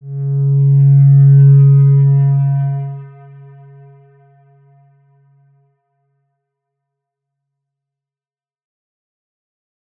X_Windwistle-C#2-pp.wav